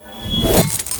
bsword2.ogg